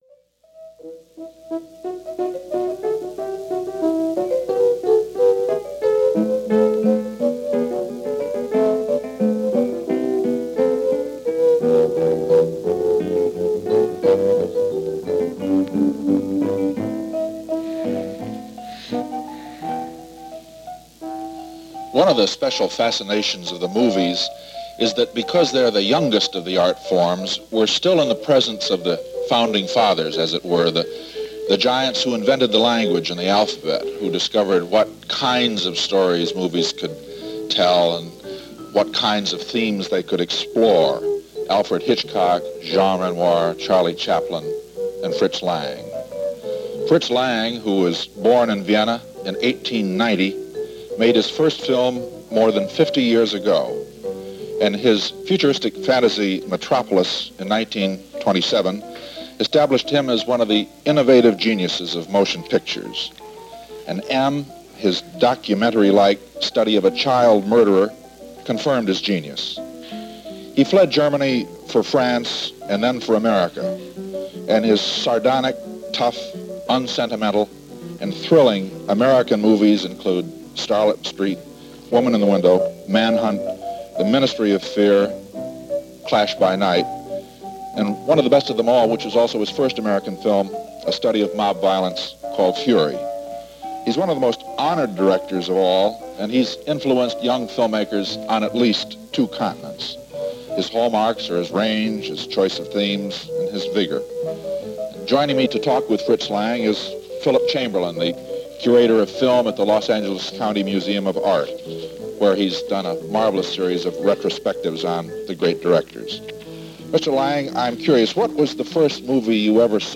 Cinema Pioneers - A Word With Fritz Lang - 1971 - An interview with the legendary Austrian-American film maker Fritz Lang: Past Daily Weekend Reference Room
Fritz-Lang-Interview-1971.mp3